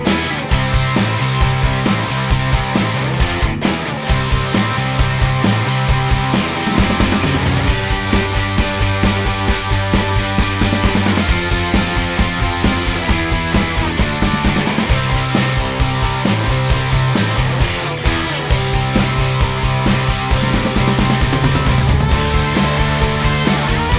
no Backing Vocals Punk 3:32 Buy £1.50